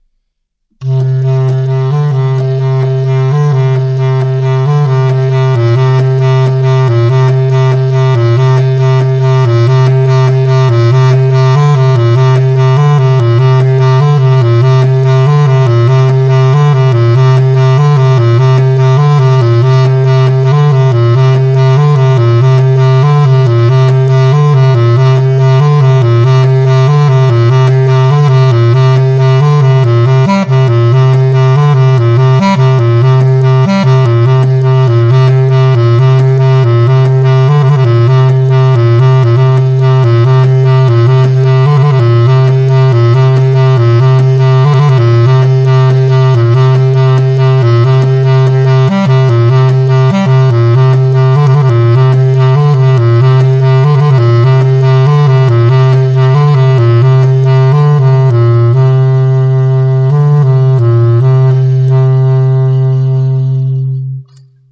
Diese Technik erlaubt gleichmäßige Tonläufe über einen langen Zeitraum, welcher nur von der Ausdauer des Spielers abhängt und zur Erfindung zusätzlicher musikalischer Texturen führt.
circ_bcl_128kb.mp3